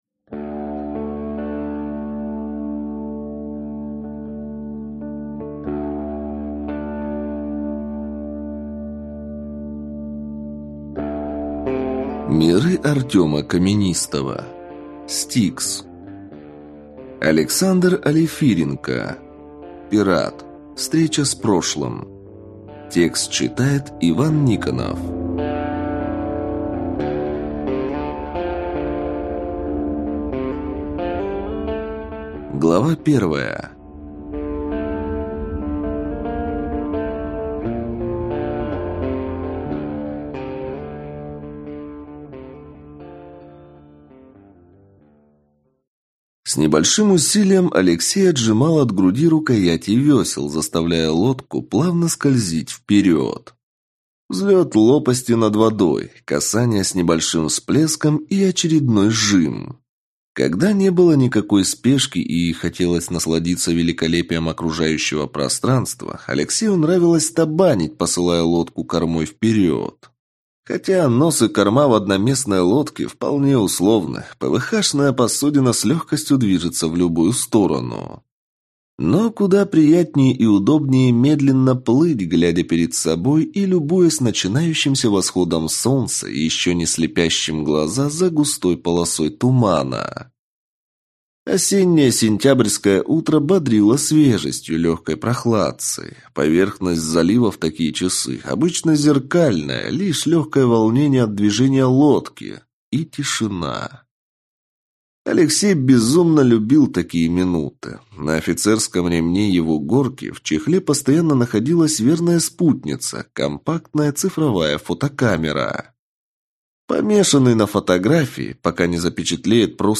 Аудиокнига S-T-I-K-S. Пират. Встреча с прошлым | Библиотека аудиокниг